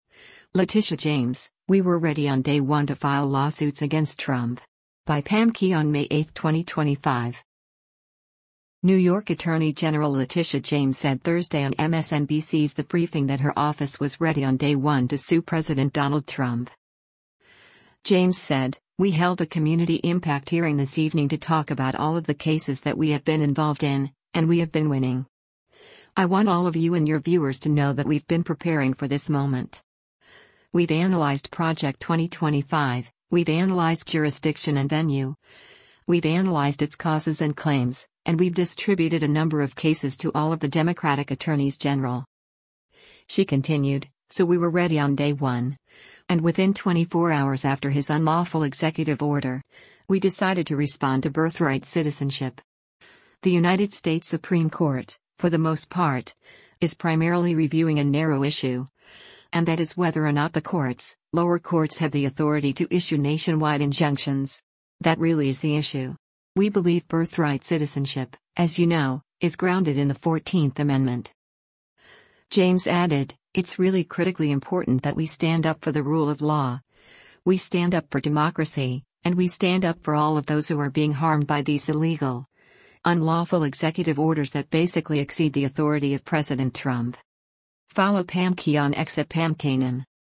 New York Attorney General Letitia James said Thursday on MSNBC’s “The Briefing” that her office was “ready on day one” to sue President Donald Trump.